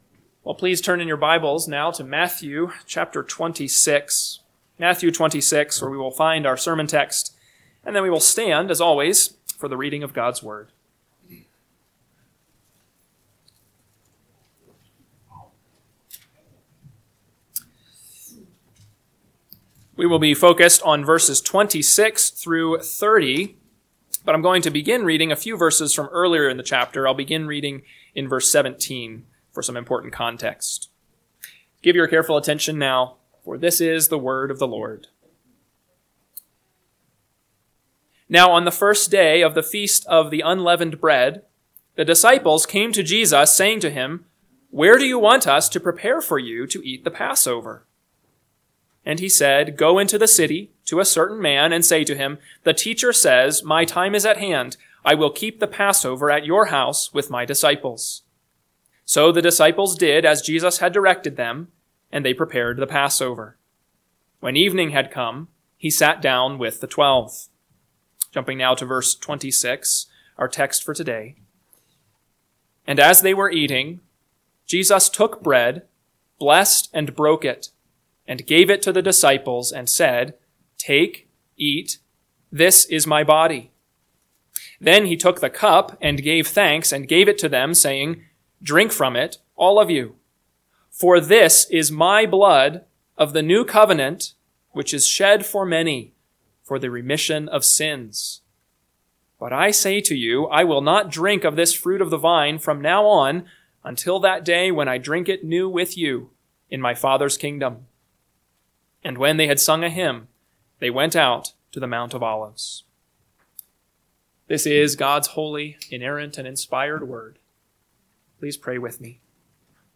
AM Sermon – 2/23/2025 – Matthew 26:26-30 – Northwoods Sermons